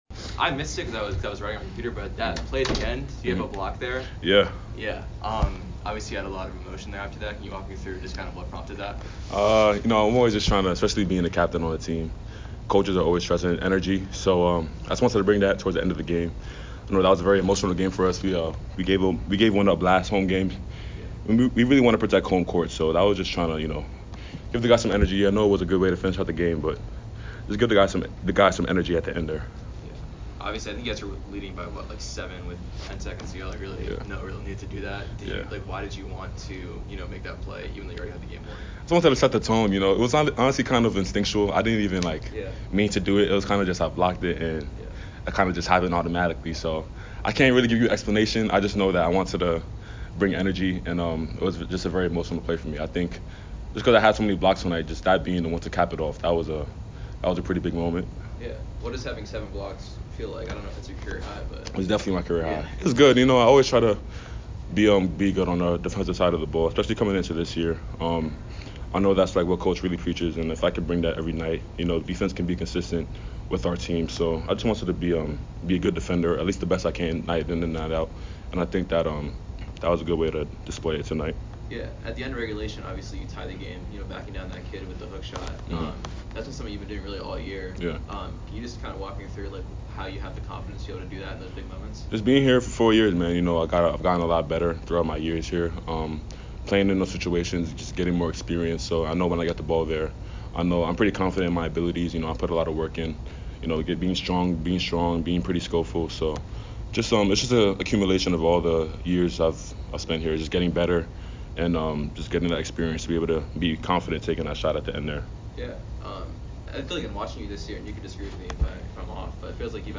Men's Basketball / UAlbany Postgame Interview (12-7-24)